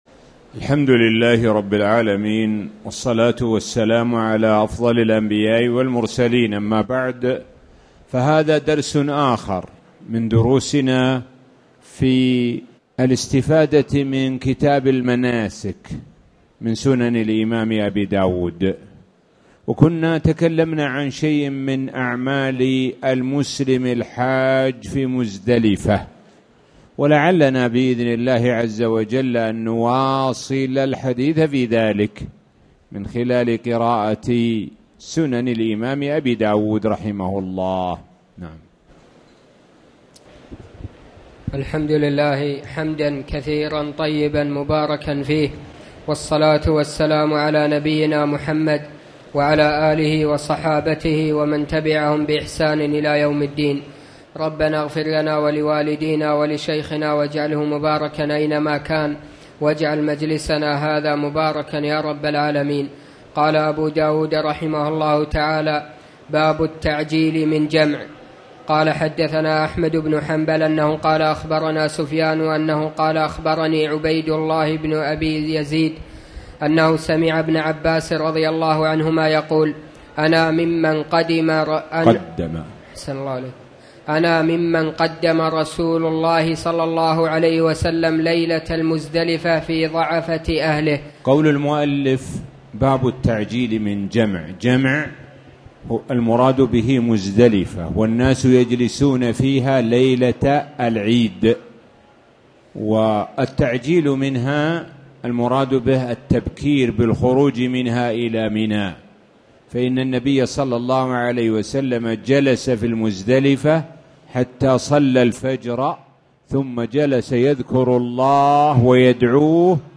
تاريخ النشر ٤ ذو الحجة ١٤٣٨ هـ المكان: المسجد الحرام الشيخ: معالي الشيخ د. سعد بن ناصر الشثري معالي الشيخ د. سعد بن ناصر الشثري في كتاب المناسك